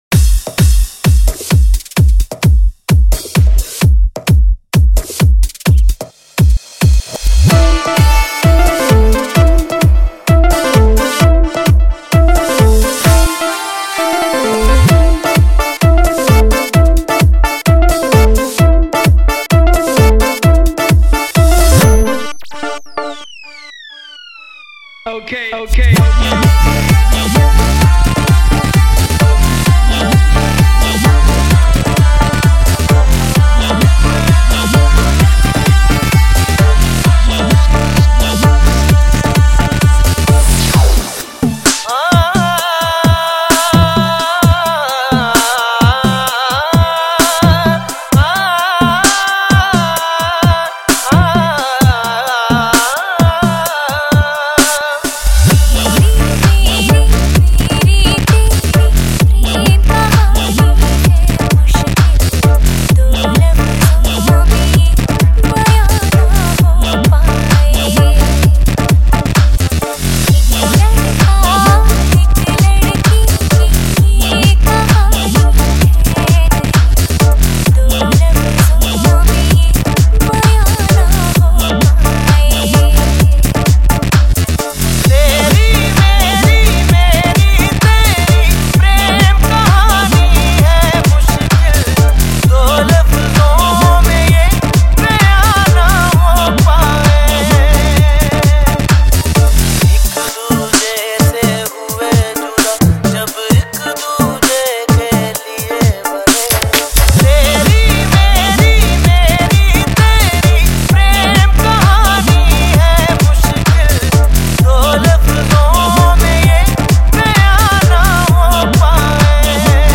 HomeMp3 Audio Songs > Others > Single Dj Mixes